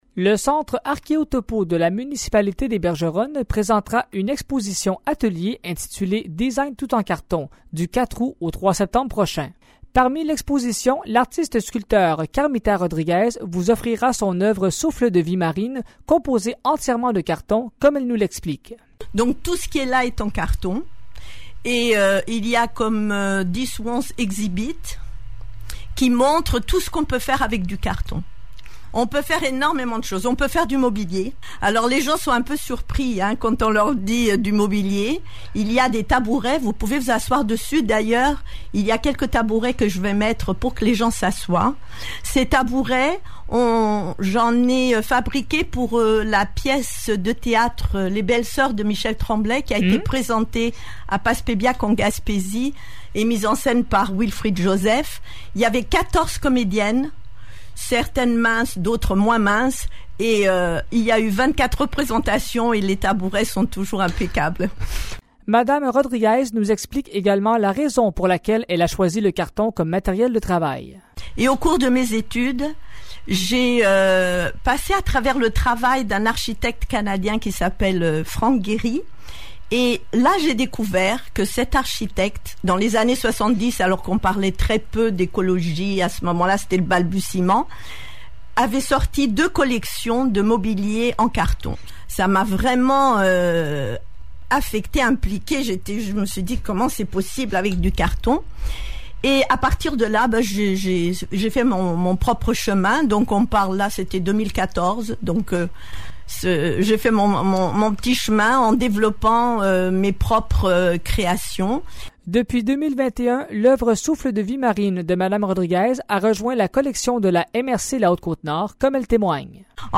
Voici le reportage